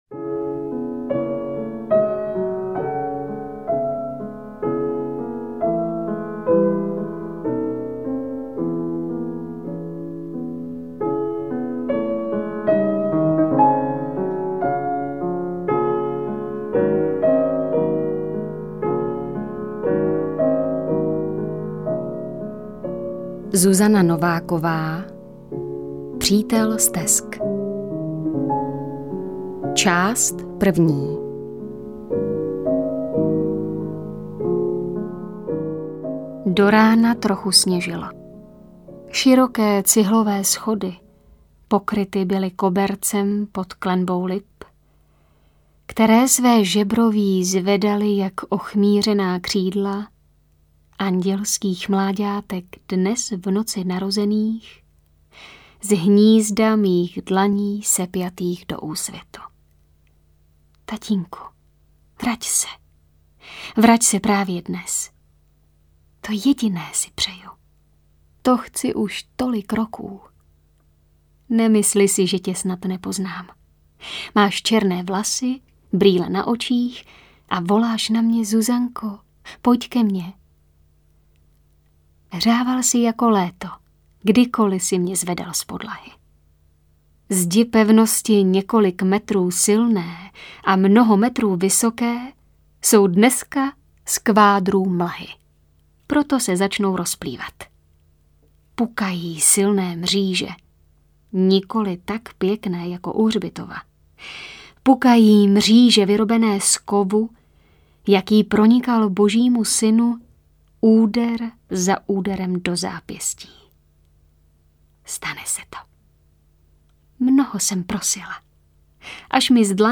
AudioKniha ke stažení, 10 x mp3, délka 3 hod. 51 min., velikost 422,7 MB, česky